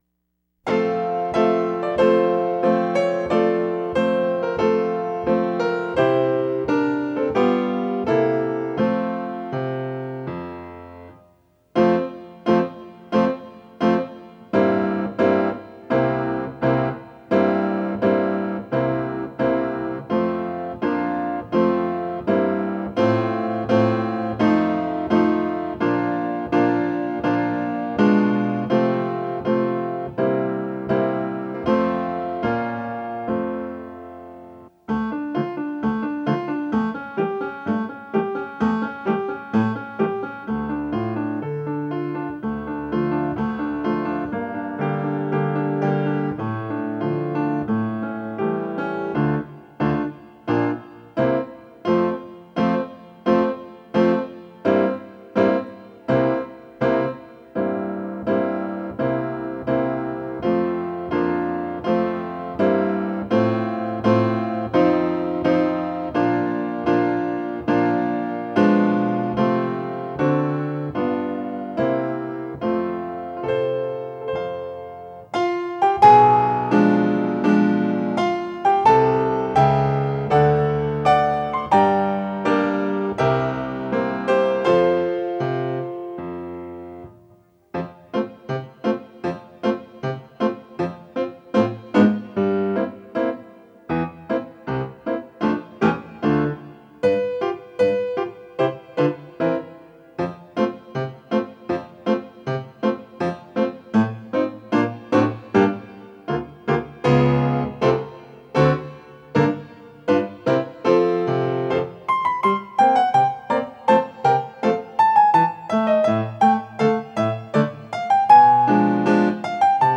Centaurus Solo with Piano Accompaniment – Performance Tempo Centaurus Solo with Piano Accompaniment – Performance Tempo Centaurus Piano Only – Performance Tempo Centaurus Piano Only – Performance Tempo
08-VanderCook_-Centaurus-Piano-Accompaniment.m4a